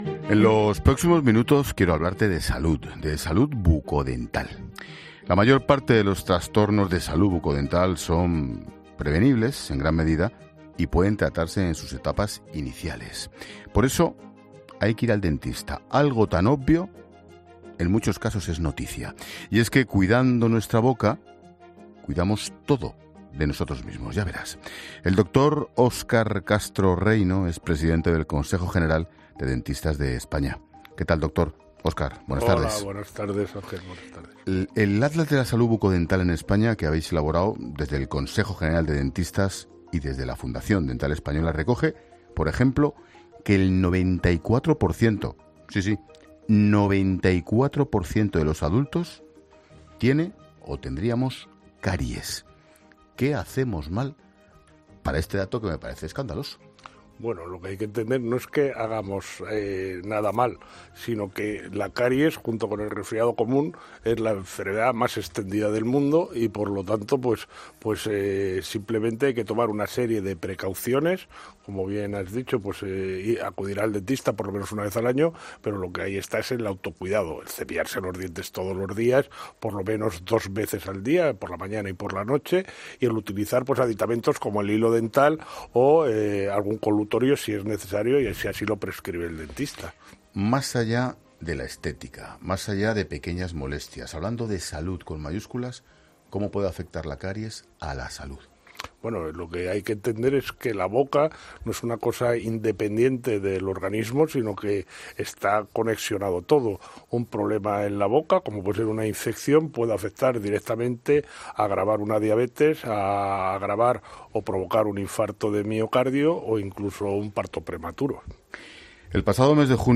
ctv-h1x-entrevista-en-la-linterna-cope-iv